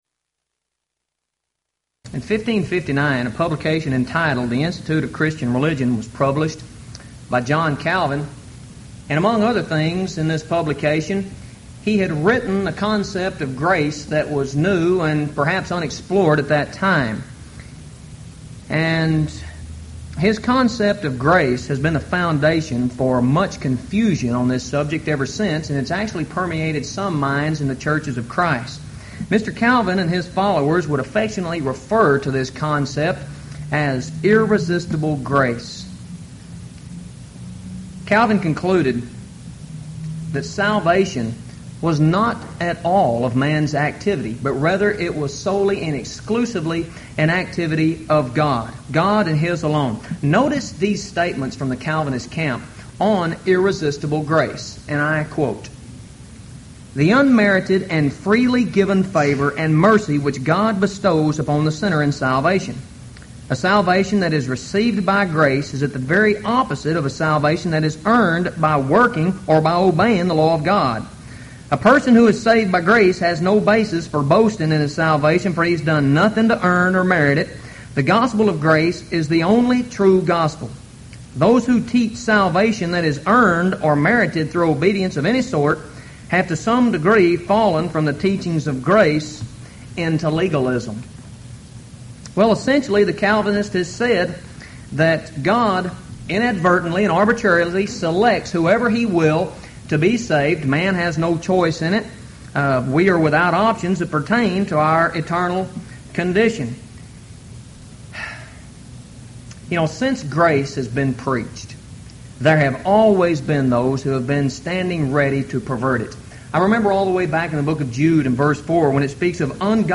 Event: 1998 Houston College of the Bible Lectures